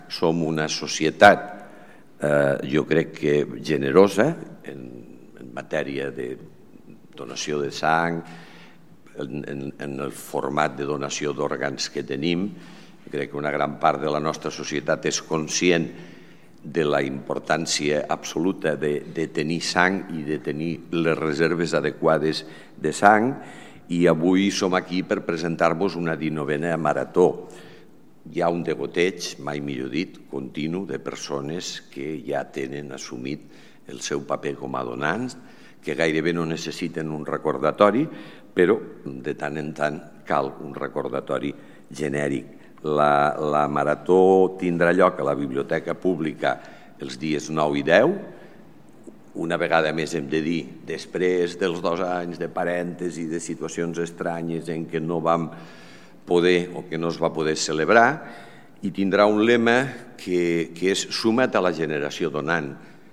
tall-de-veu-de-llalcalde-miquel-pueyo-sobre-la-19-marato-de-donants-de-sang-a-lleida